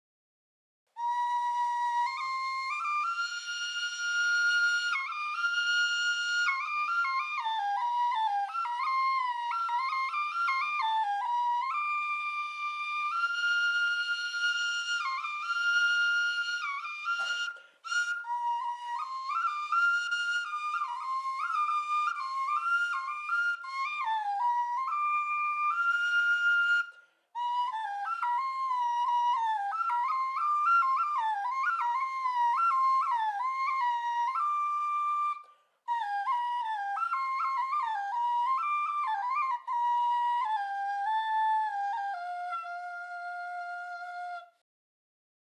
Shan music
duct flute solo courting music
Track 13 Shan flute.mp3